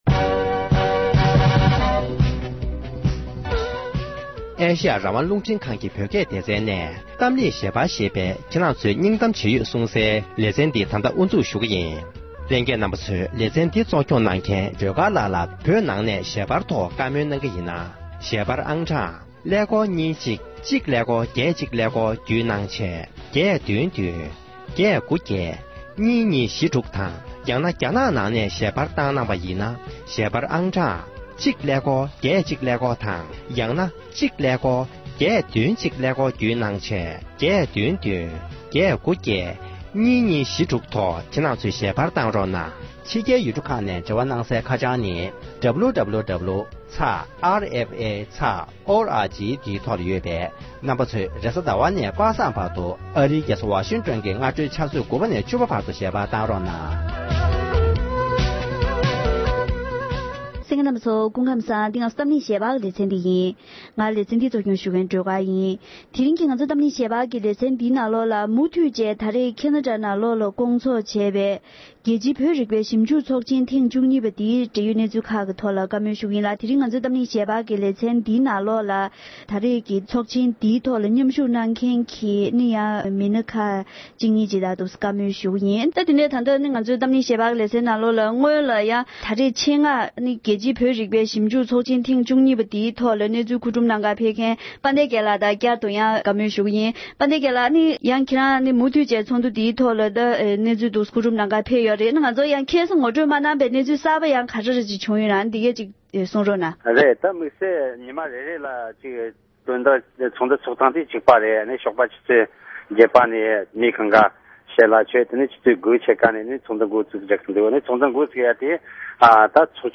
ཁེ་ན་ཌའི་ནང་ཚོཊ་བཞིན་པའི་རྒྱལ་སྤྱིའི་བོད་རིག་པའི་ཞིབ་འཇུག་ཚོཊ་ཆེན་ཐེངས་༡༢པའི་ནང་མཉམ་ཞུཊ་གནང་མཁན་མི་སྣ་ཁག་དང་བཀའ་མོལ།